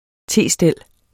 Udtale [ ˈteˀ- ]